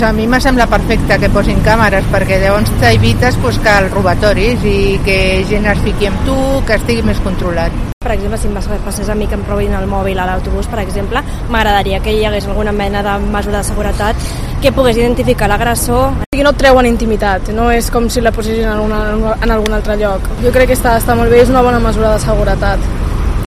Así valoran algunas usuarias de autobuses de Barcelona la medida adoptada por TMB